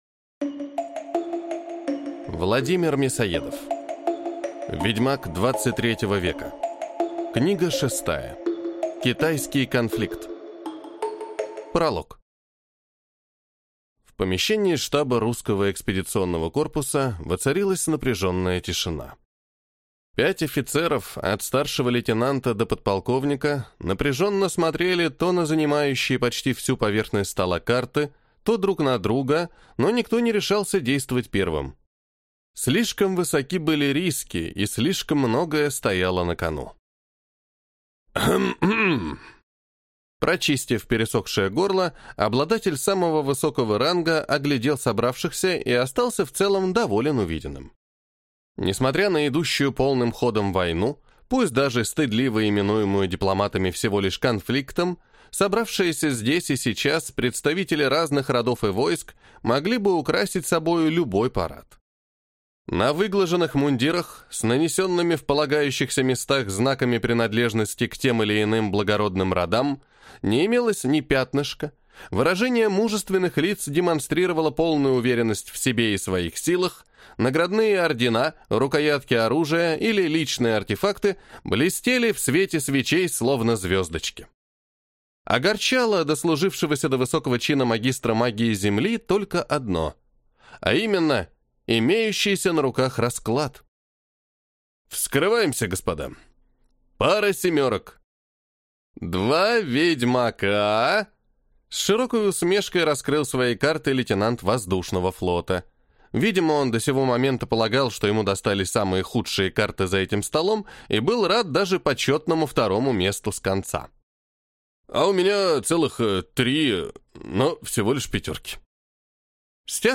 Аудиокнига Китайский конфликт | Библиотека аудиокниг
Прослушать и бесплатно скачать фрагмент аудиокниги